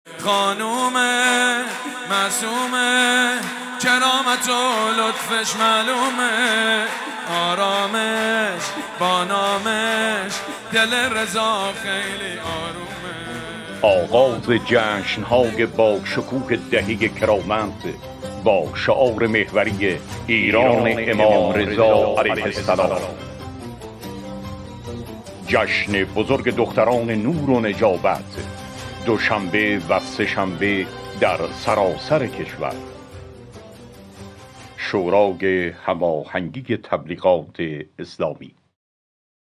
تیزر اطلاع رسانی برنامه های دهه کرامت
تیزر دهه کرامت_صوت.mp3